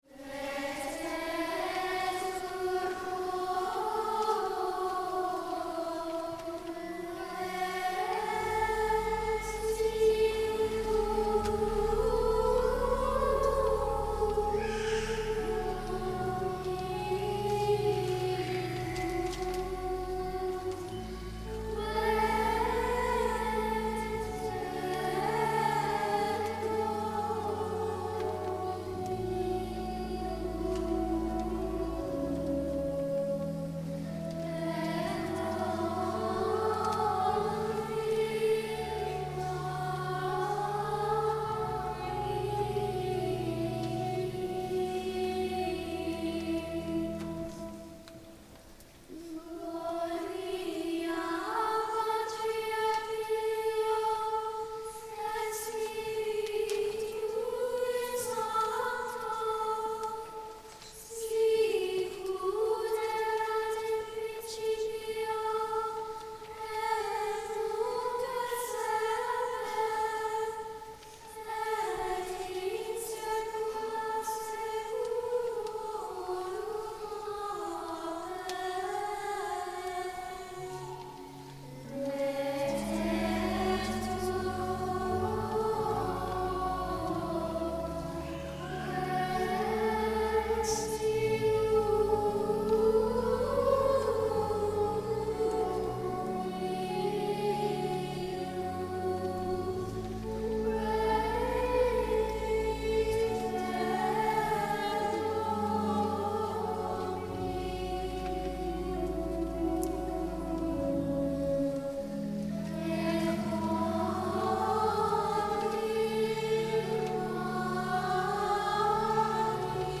For young singers, especially those just beginning to sing Gregorian chant, this kind of kinesthetic and aural exercise is invaluable.
Mp3 Download • Live Rec. (“Lætétur cor quæréntium Dóminum”)
organist.
Laetetur-cor-quaerentium-Dominum-Childrens-Choir.mp3